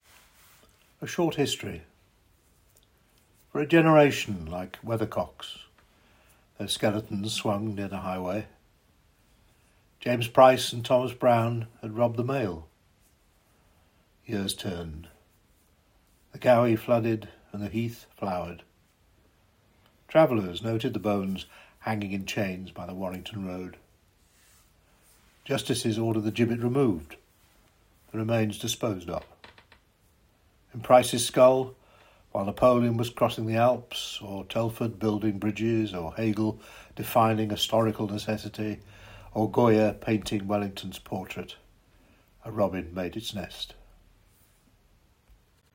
POETRY VOICE-OVERS 1: ‘A SHORT HISTORY’